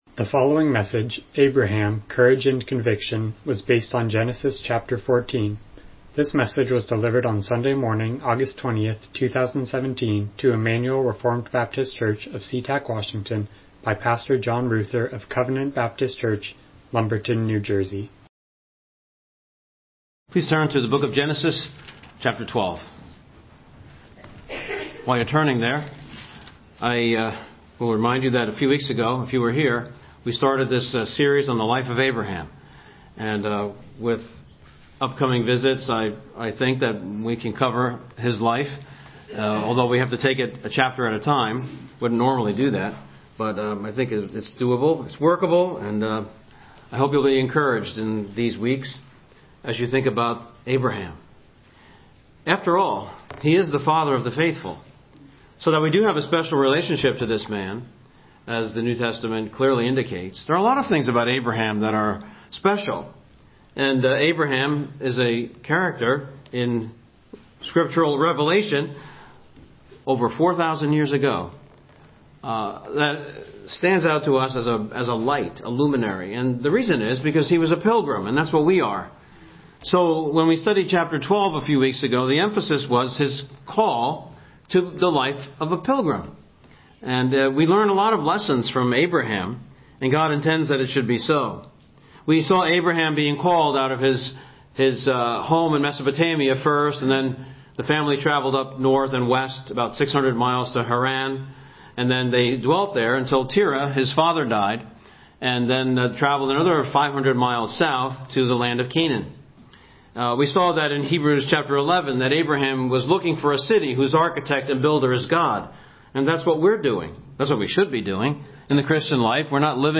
Genesis 14:1-24 Service Type: Morning Worship « Church Membership